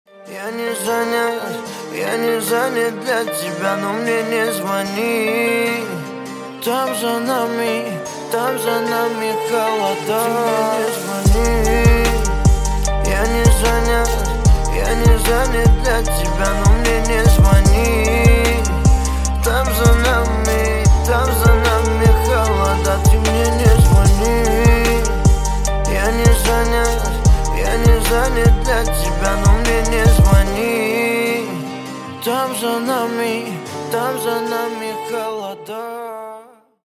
Спокойные И Тихие Рингтоны » # Грустные Рингтоны
Рэп Хип-Хоп Рингтоны